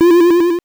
8 bits Elements
powerup_30.wav